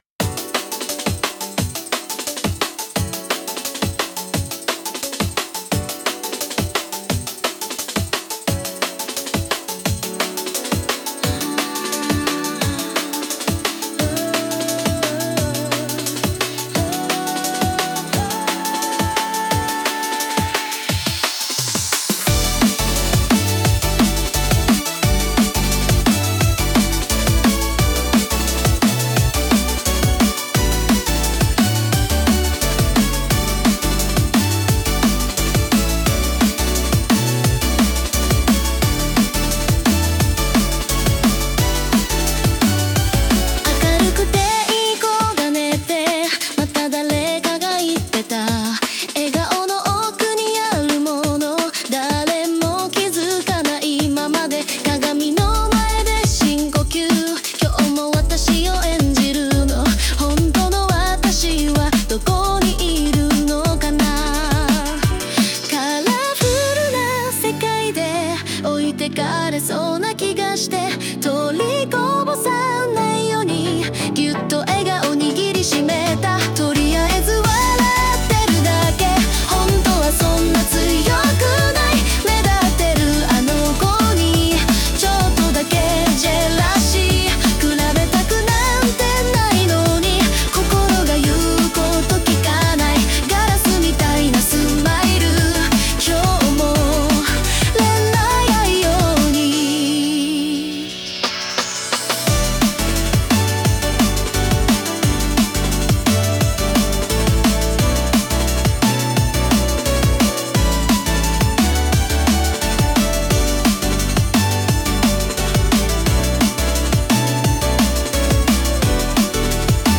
そんな感じなのAIに作らせてみた